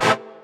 Brass Stab.wav